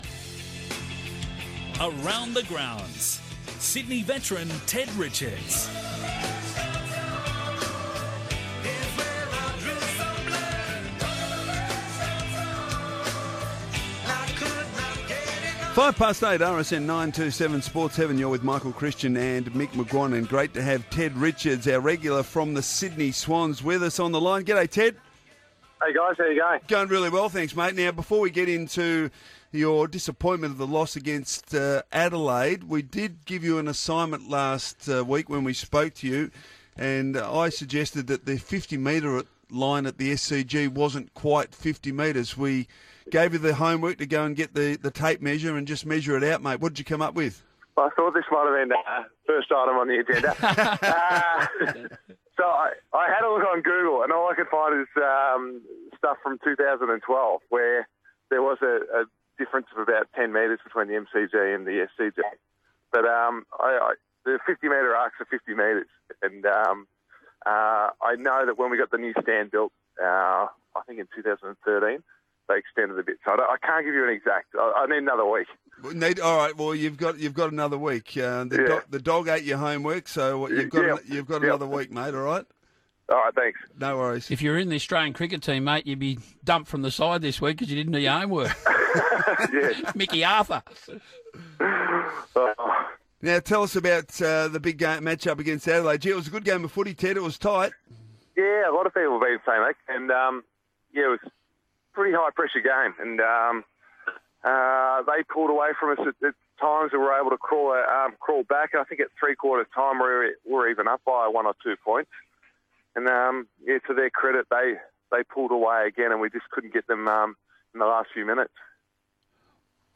Defender Ted Richards joins the team on Radio Sport National.